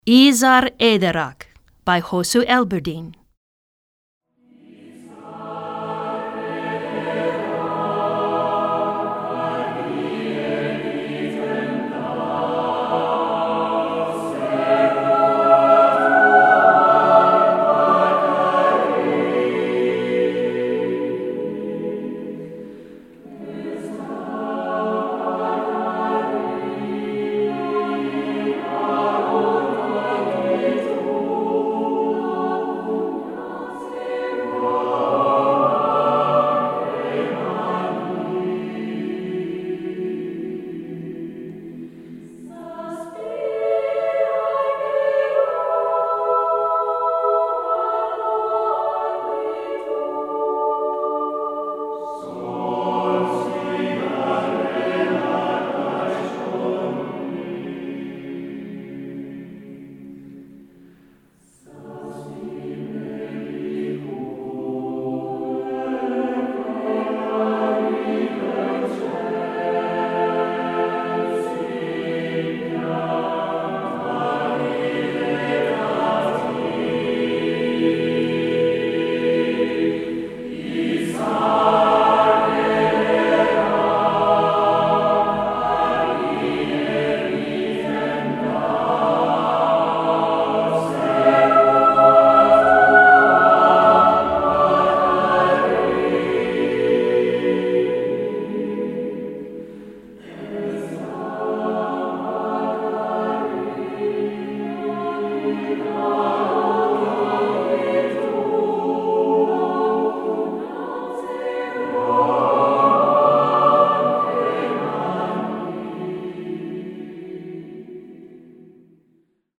Choeur Mixte SATB a Cappella